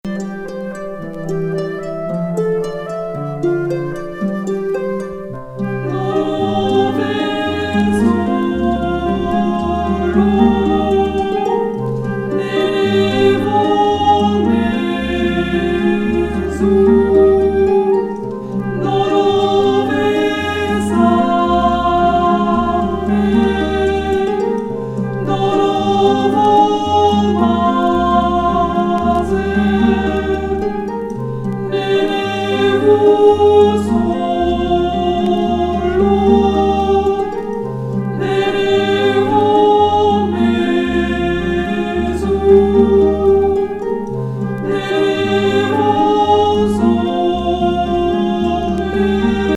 賛美歌のように美しいハーモニーを奏でるA面
パイプ・オルガンのような音色のオルガン・ソロB面